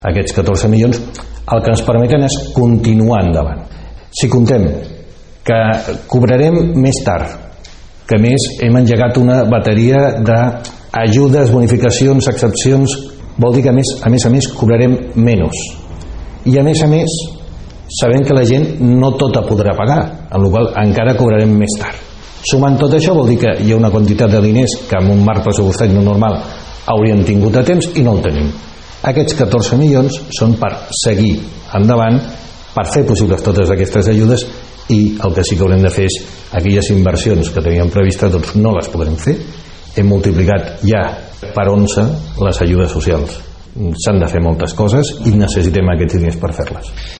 En una entrevista a RP, el batlle blanenc ha enumerat alguna de les coses que encara falten per fer a la ciutat veïna, però que l’actual situació pot endarrerir, com ara la nova piscina municipal, la prefectura de policia o el parc i zona verda principal de la vila.
canosa-entrevista-1-.mp3